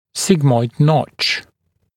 [‘sɪgmɔɪd nɔʧ][‘сигмойд ноч]сигмовидная вырезка (нижней челюсти)